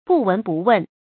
注音：ㄅㄨˋ ㄨㄣˊ ㄅㄨˋ ㄨㄣˋ
不聞不問的讀法